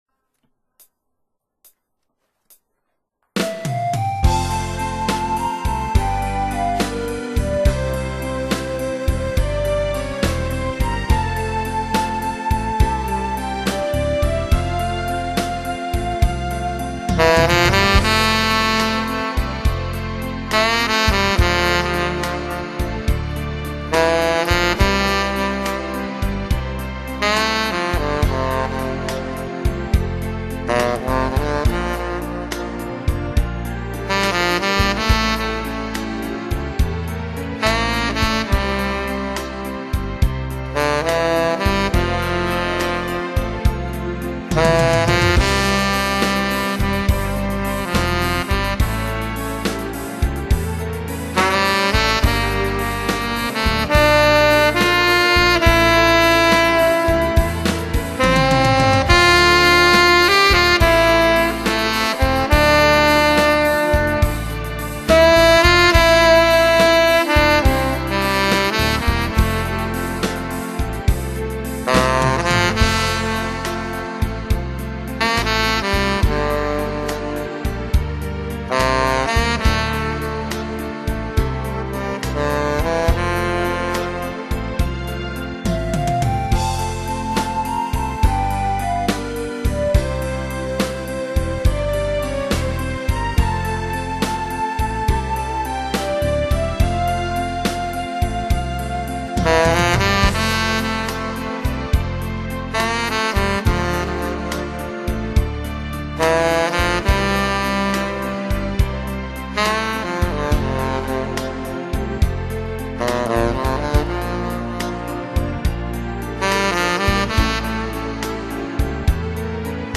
아마추어의 색소폰 연주
이번에는 처음으로 동호회 연습실에서 정식 녹음장비로 녹음했습니다.
느낌은 꼭 초등생 국어책 읽듯이 감정은 하나도 없구...